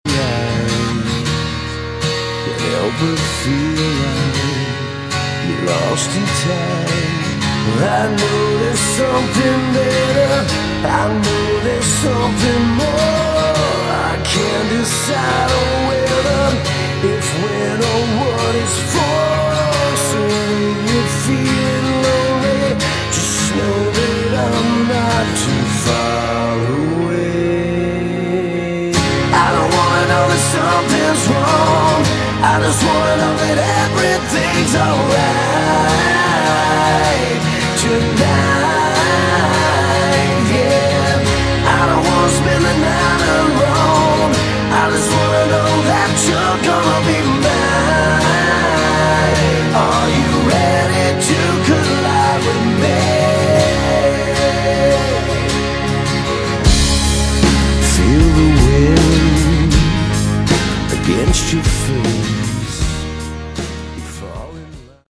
Category: Hard Rock
vocals, guitar, keyboards
drums
bass
guitar